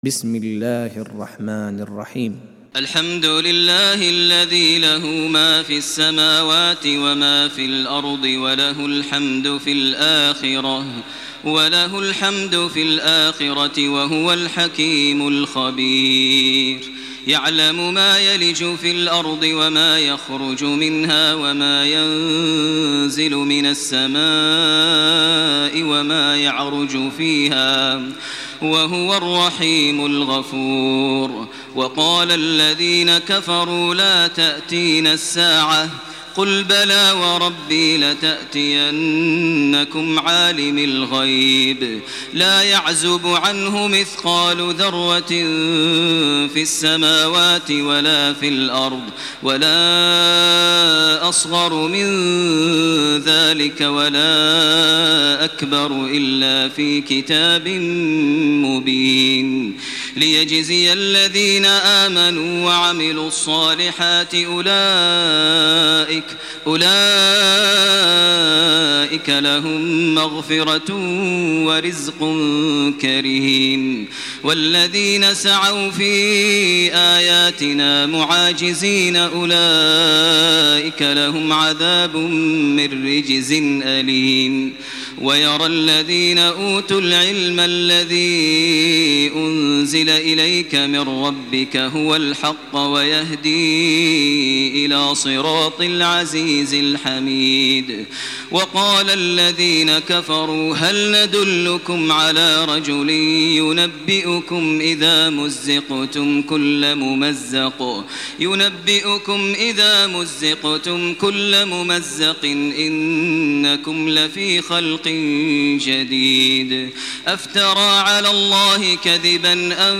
تراويح ليلة 22 رمضان 1428هـ سورتي سبأ و فاطر Taraweeh 22 st night Ramadan 1428H from Surah Saba to Faatir > تراويح الحرم المكي عام 1428 🕋 > التراويح - تلاوات الحرمين